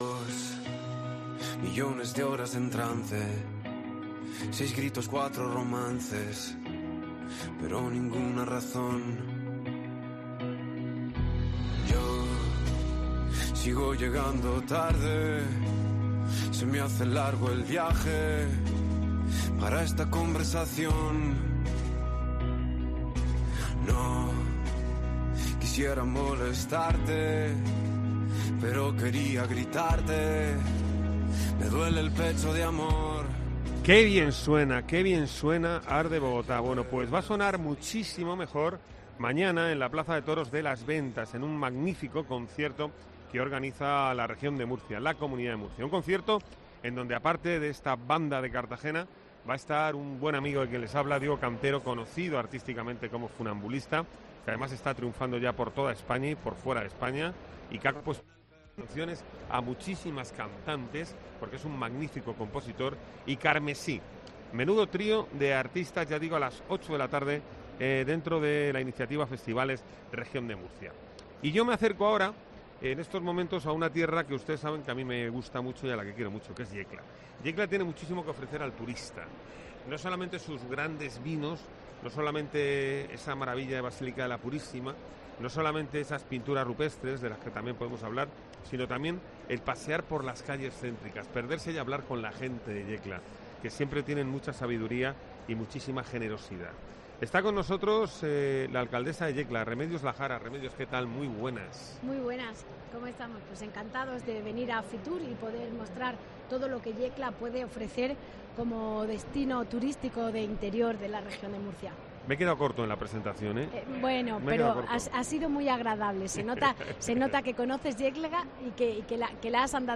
Remedios Lajara, alcaldesa e Isabel Pérez, concejal de Turismo de Yecla, en COPE Región
La alcaldesa de Yecla, Remedios Lajara, acompañada por la concejal de Turismo, Isabel Pérez, han pasado por el set de COPE para contar la variada oferta que proponen en FITUR.